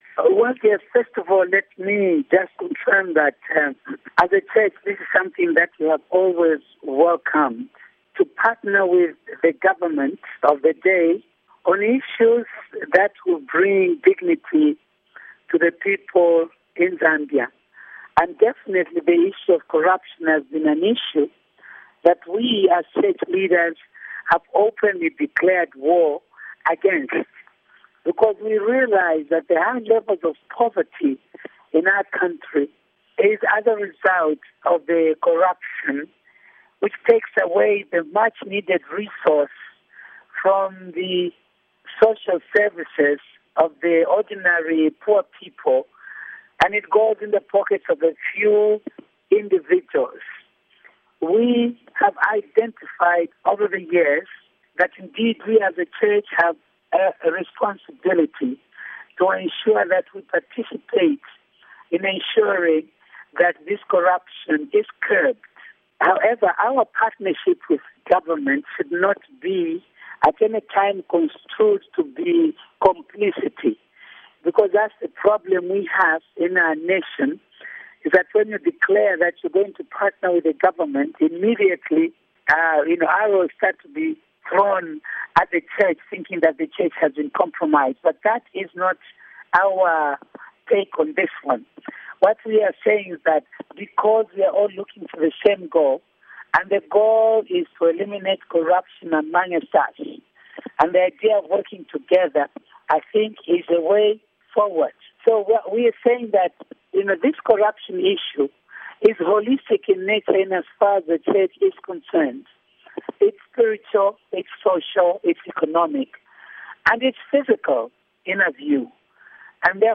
intervieiw